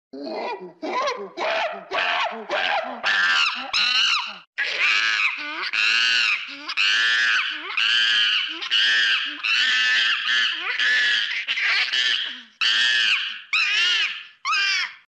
แจกเสียงลิง Sound Effects Free Download